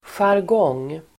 Folkets service: jargong jargong substantiv, jargon Uttal: [sjarg'ång:] Böjningar: jargongen, jargonger Definition: typiskt sätt att uttrycka sig (inom en yrkesgrupp etc) Sammansättningar: läkarjargong (medical jargon)